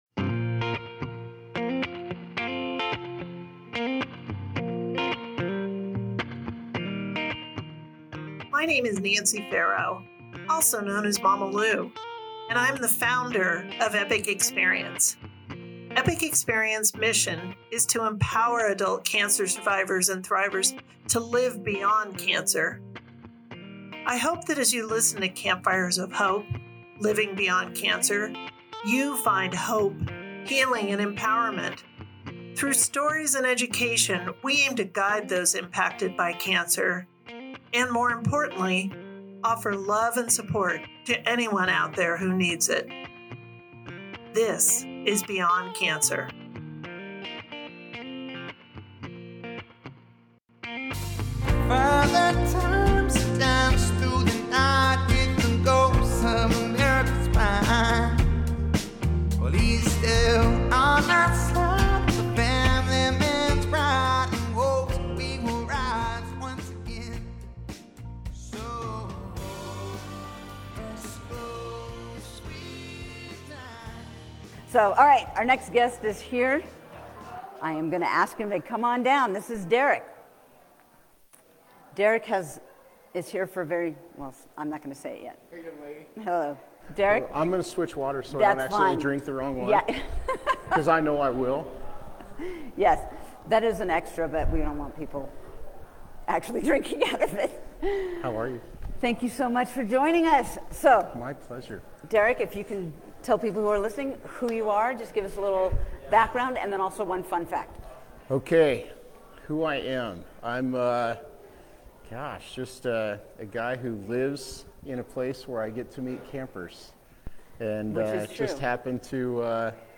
Live from the Hearts & Hope Gala (Part 2)